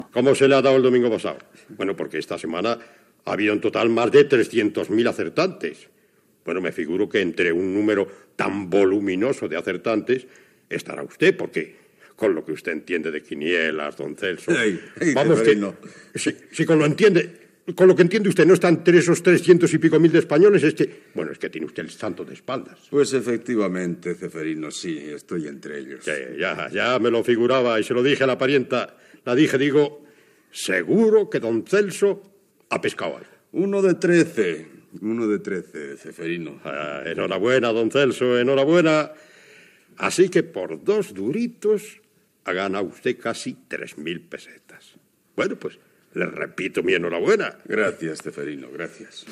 Diàleg ficcionat entre un apostant, don Celso, i el dependent d'un despatx d'administració de travesses, Ceferino.